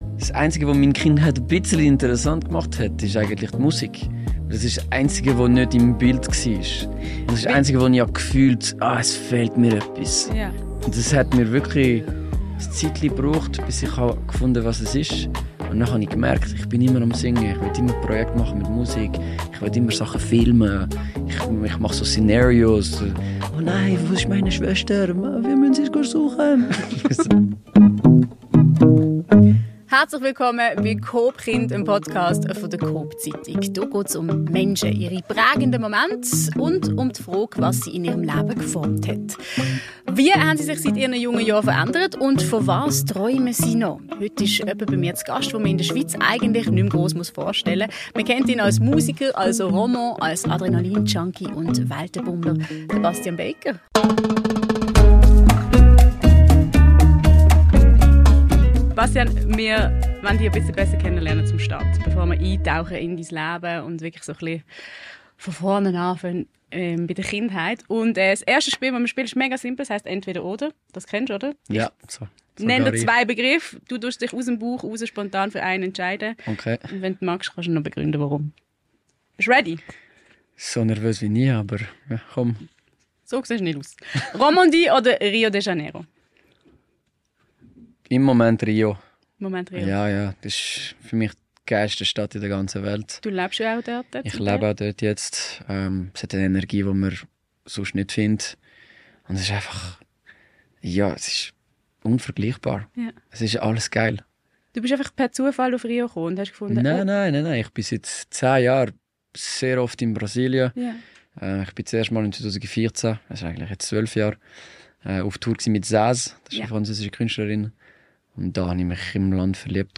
Der fulminante Auftakt zum neuen coop.chind Podcast: Host Jennifer Bosshard trifft auf Bastian Baker und plaudert mit ihm aus dem Nähkästchen. Kindheit, Karriere, Leidenschaft, Freiheit – kein Thema bleibt unberührt.